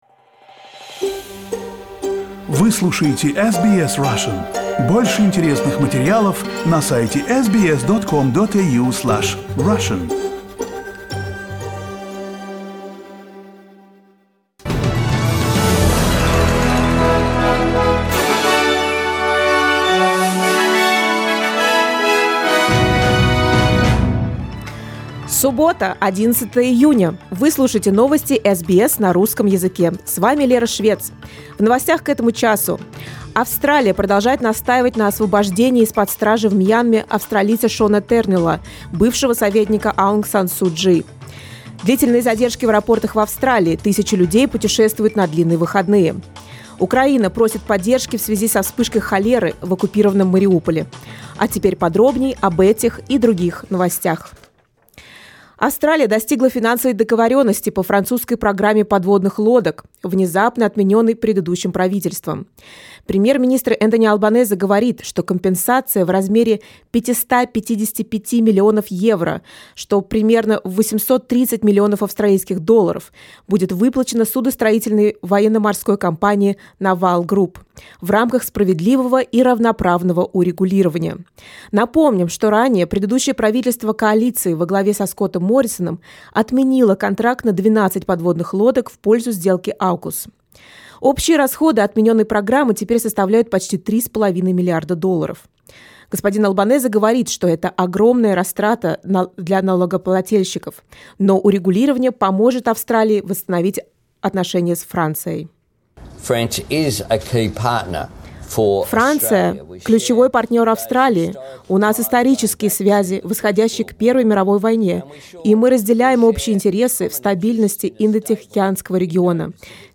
SBS news in Russian — 11.06.22